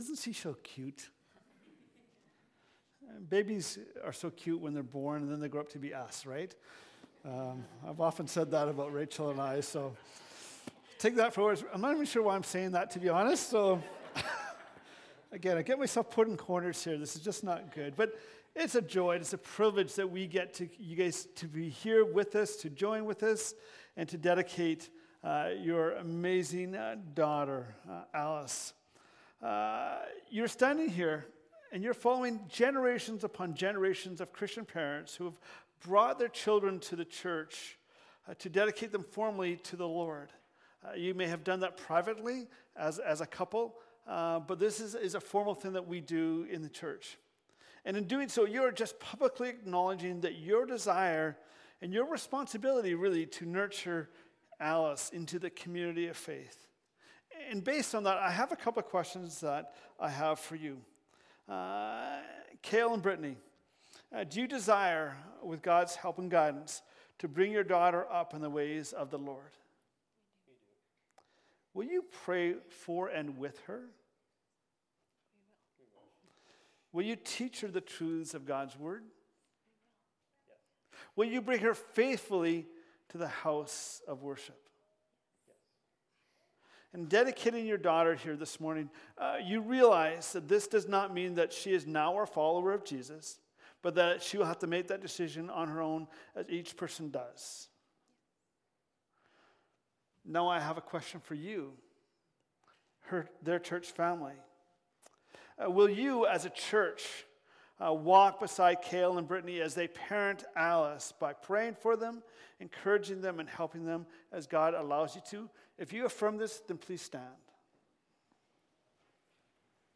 Sermons | Richmond Park Church